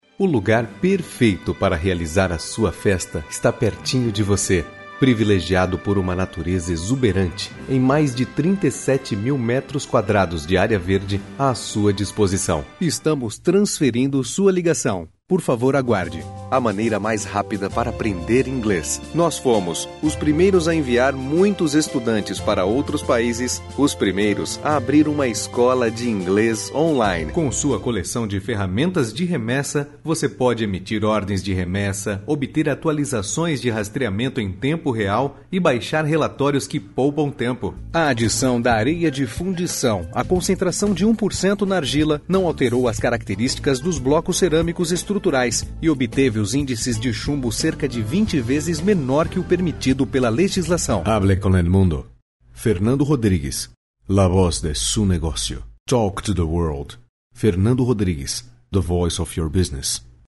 It can be fun, sexy, professional or smooth depending on the type of recording.
Sprechprobe: Industrie (Muttersprache):